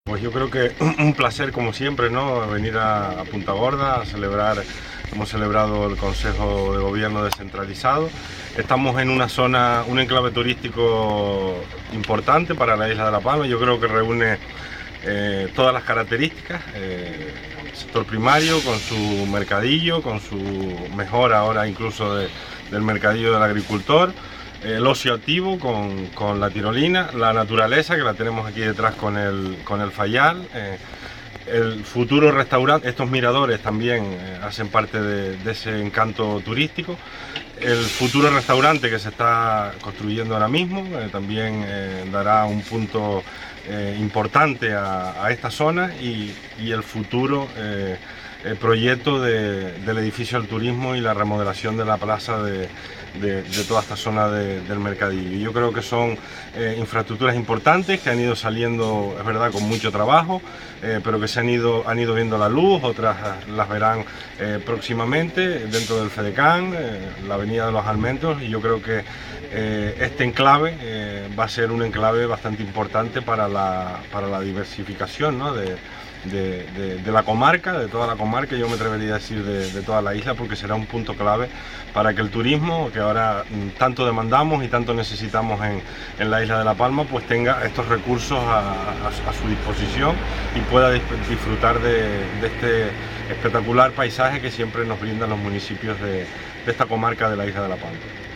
Declaraciones audio Borja Perdomo.mp3